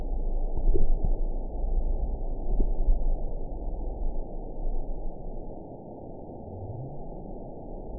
event 922638 date 02/13/25 time 23:44:35 GMT (8 months, 1 week ago) score 9.57 location TSS-AB10 detected by nrw target species NRW annotations +NRW Spectrogram: Frequency (kHz) vs. Time (s) audio not available .wav